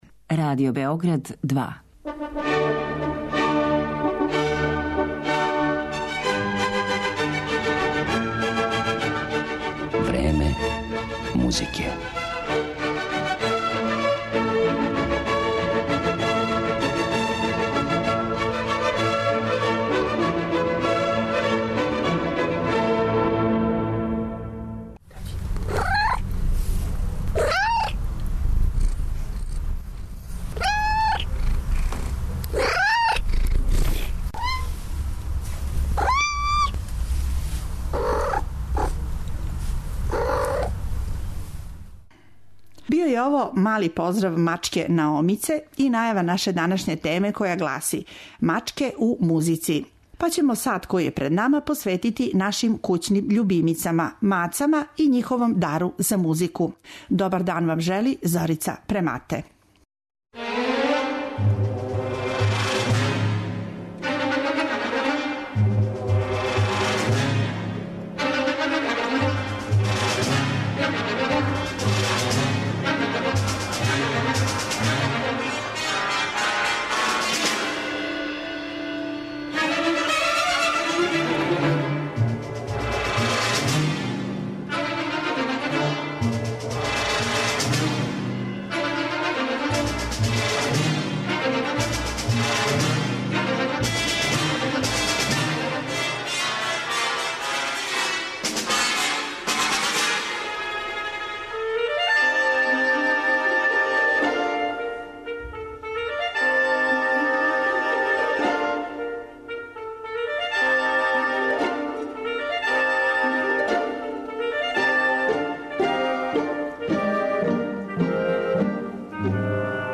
Чућете ведар, необичан и разноврстан музички материјал